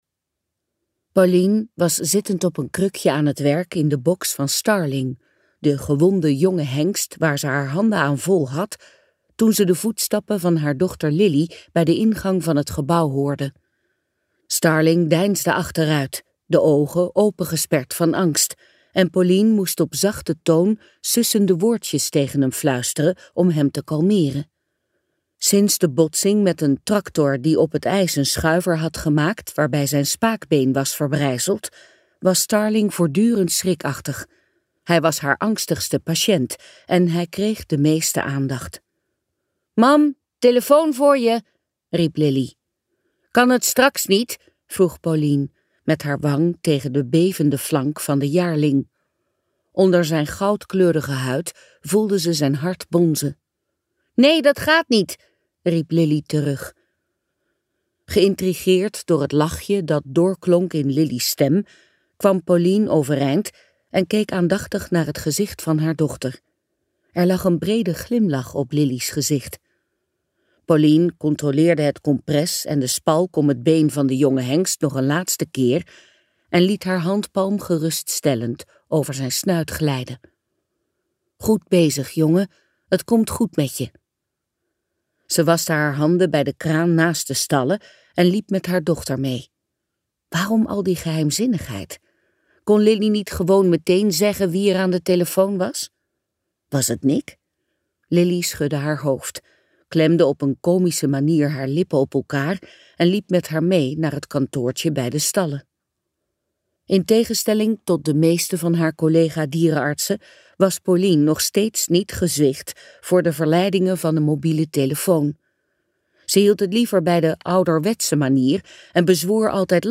Sterrenstof luisterboek | Ambo|Anthos Uitgevers